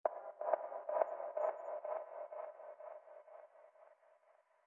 cave_taps.wav